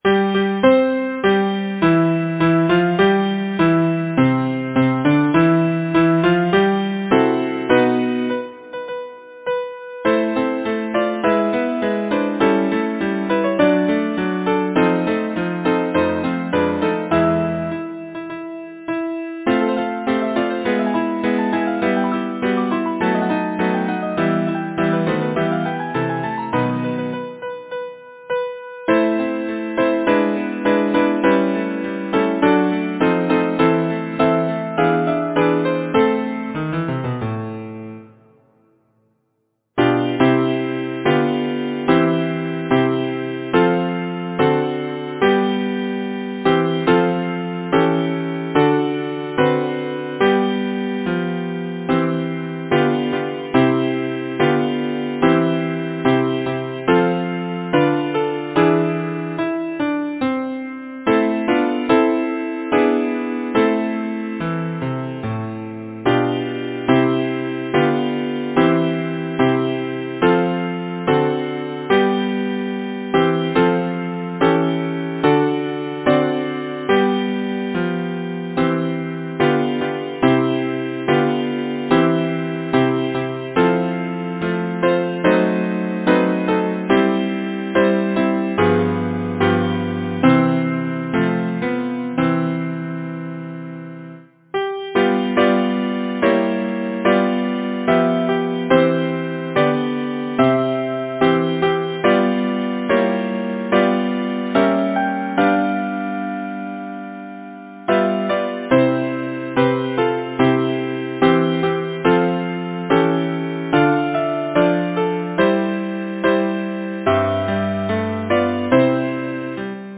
Language: English Instruments: Piano